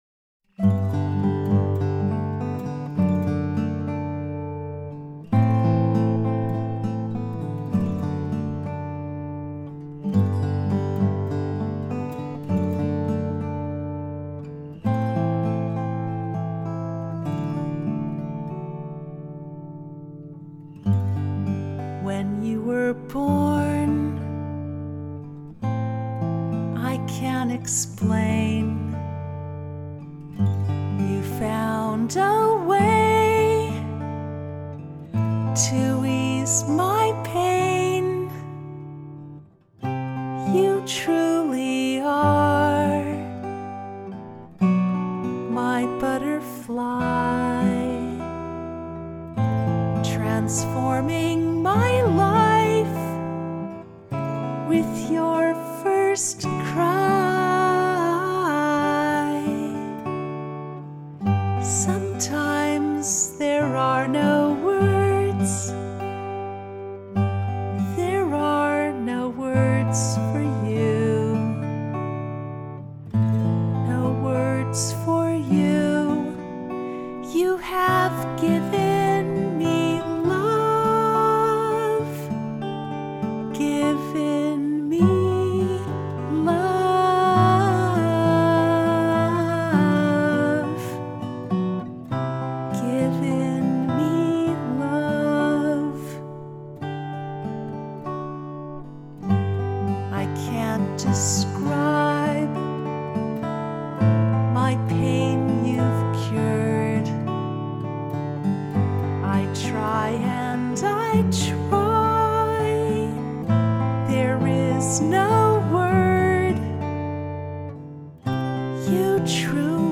Below is both a vocal and guitar instrumental for it:
no-words-acoustic-6-13-20.mp3